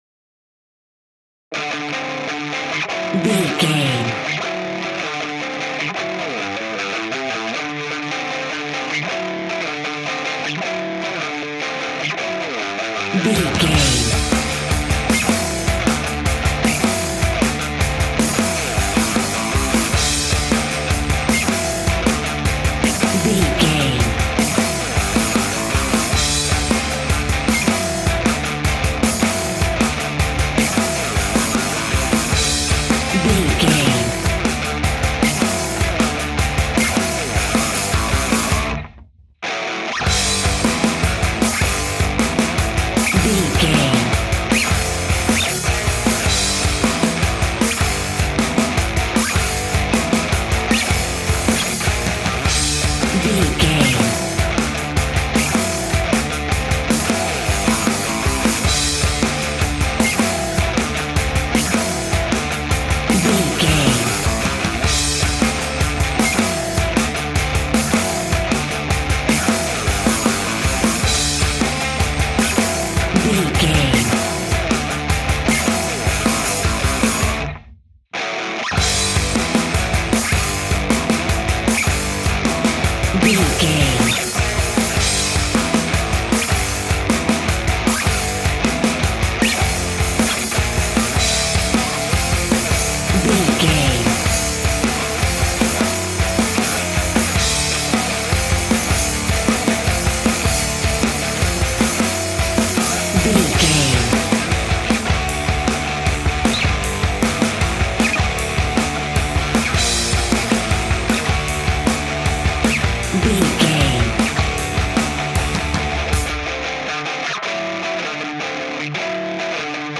Uplifting
Ionian/Major
D
hard rock
distortion
instrumentals
rock guitars
Rock Bass
heavy drums
distorted guitars
hammond organ